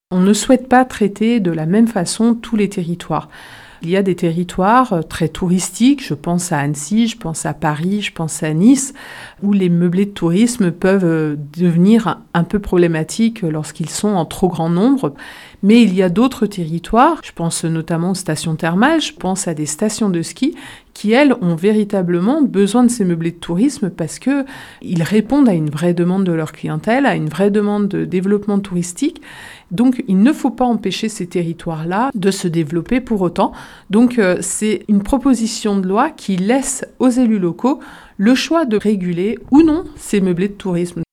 Sylviane Noël est sénatrice de Haute-Savoie, et rapporteure du texte.